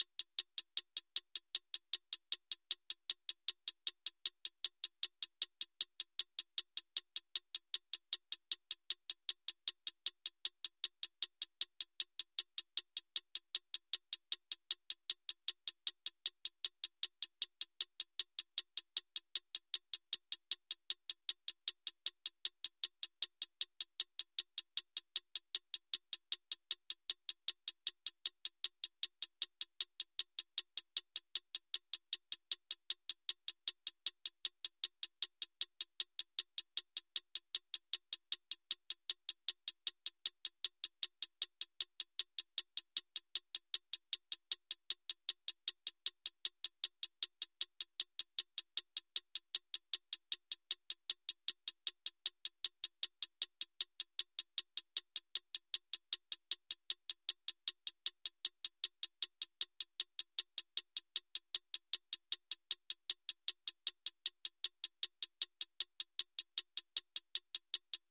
【効果音】脱進機の音 - ポケットサウンド - フリー効果音素材・BGMダウンロード
機械式時計での重要パーツ、脱進機のチクタク音の効果音素材です。